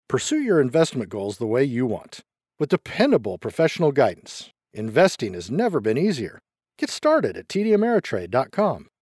Professional Voice Talent, Artists and Actors Online
Adult (30-50) | Older Sound (50+)